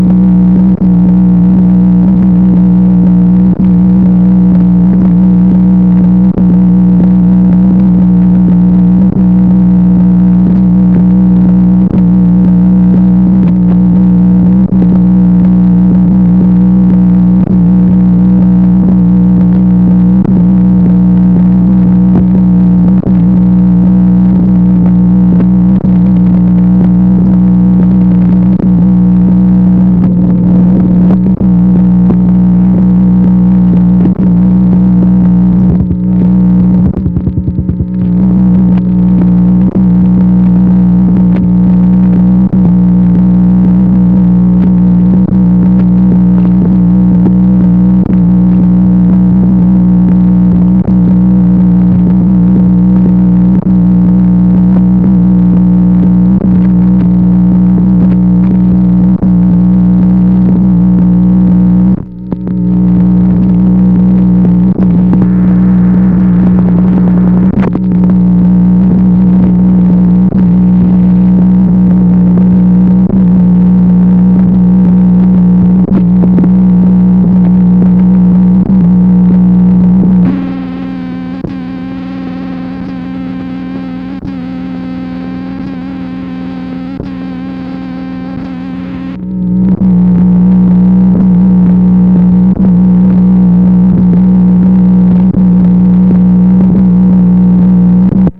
MACHINE NOISE, February 12, 1964
Secret White House Tapes | Lyndon B. Johnson Presidency